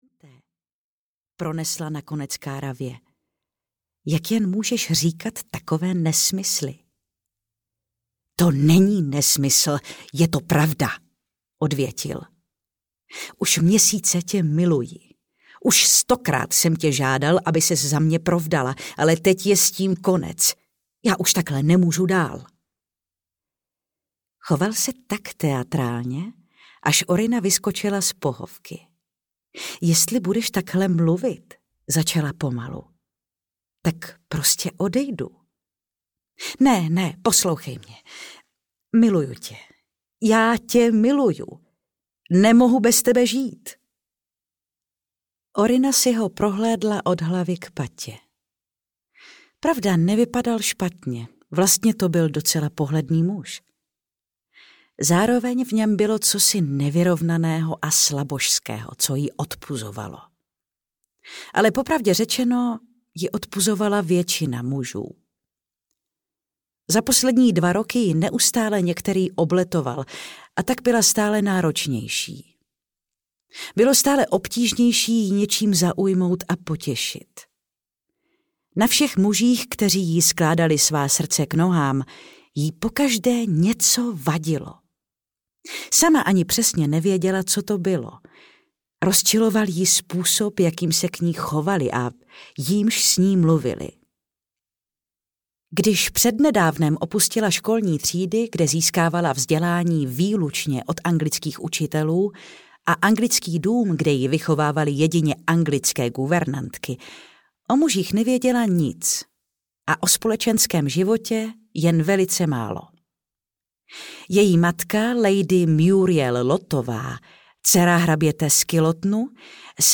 Zázrak v Mexiku audiokniha
Ukázka z knihy